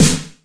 Snare - Roland TR 41